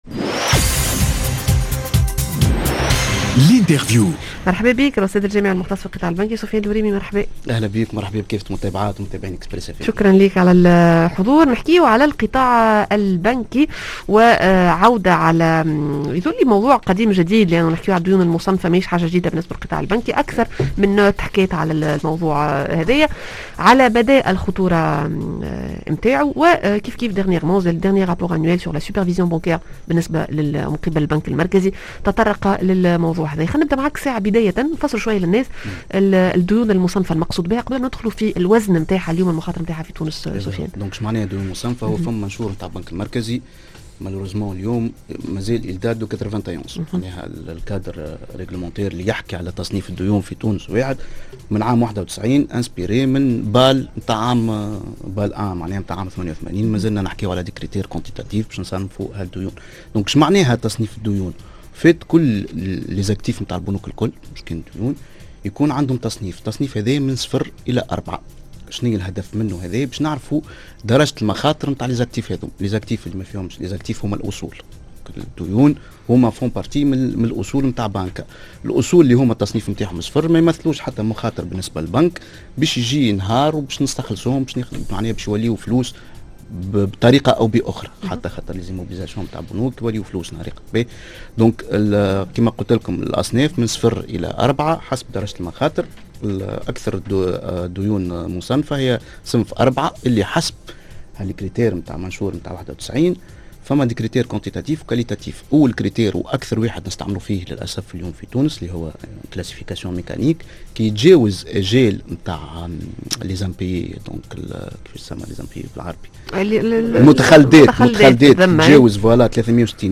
L'interview: الديون المصنّفة شكون المسؤول عليها و كيفاش تتمّ تسويتها؟